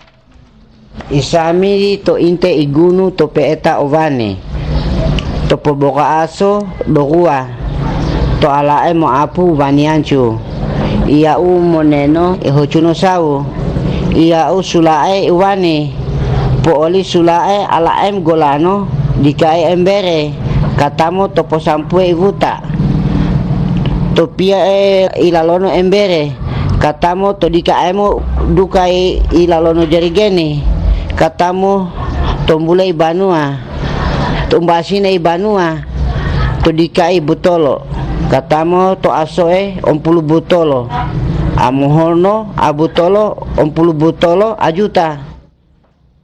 This paper presents an 844-item word list of the Wasambua dialect of the Cia-Cia language of southern Buton Island, southeastern Sulawesi, Indonesia.
It concludes with a brief, 14-sentence personal narrative.